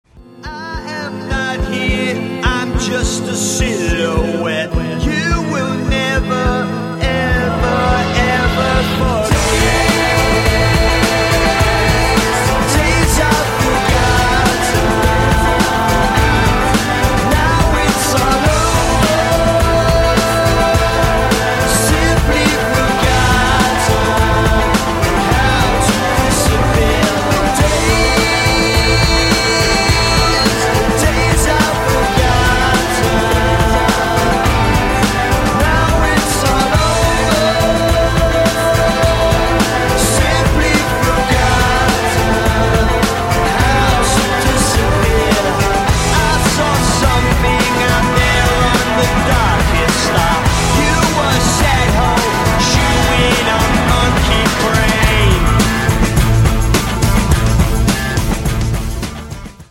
• Качество: 128, Stereo
гитара
мужской вокал
рок
Альтернативный рок